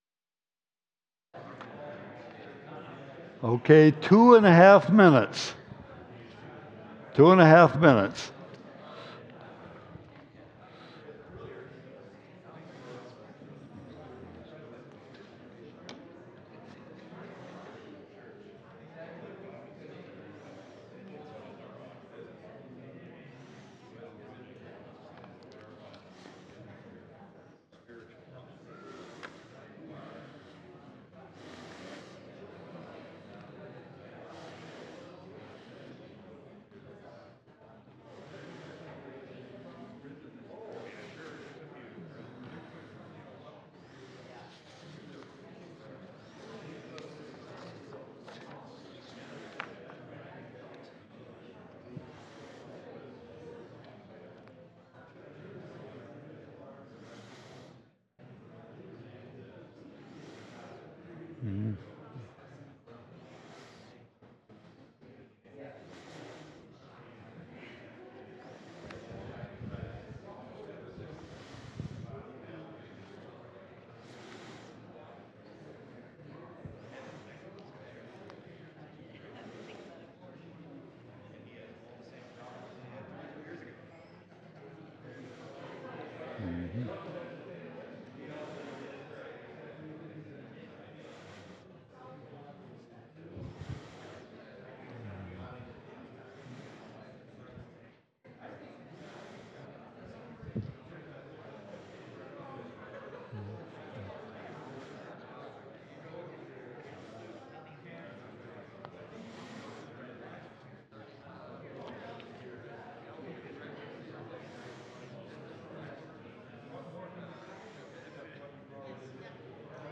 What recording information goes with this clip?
Our apologies that the audio for this class does not include the whole class but picks up in the middle while groups are discussing the scripture passages and accompanying questions which are linked below. This audio contains a bit of reporting back from the small group breakout discussions.